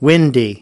5. windy /ˈwɪndi/: có gió
windy.mp3